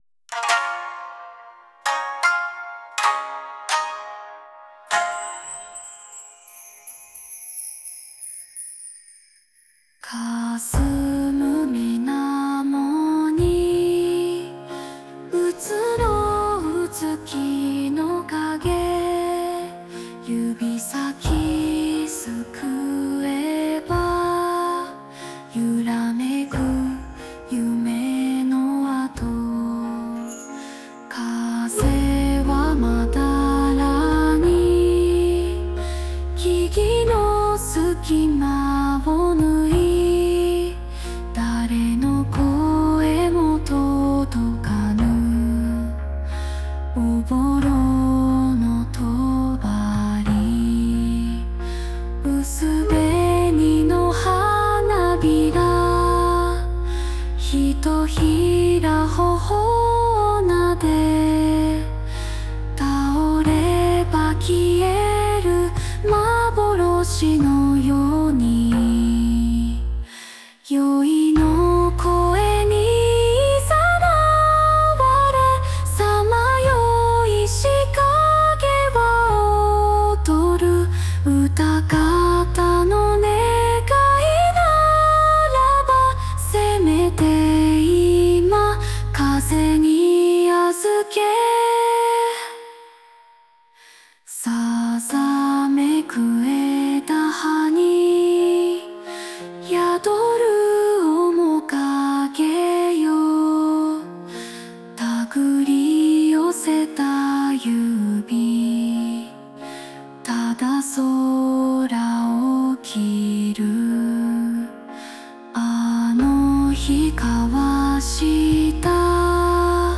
邦楽女性ボーカル著作権フリーBGM ボーカル
女性ボーカル邦楽邦楽 女性ボーカルエンドロール披露宴BGM入場・再入場バラード和風切ないノスタルジック
著作権フリーオリジナルBGMです。
女性ボーカル（邦楽・日本語）曲です。